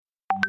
스사모 - 벨소리 / 알림음
알림음 Samsung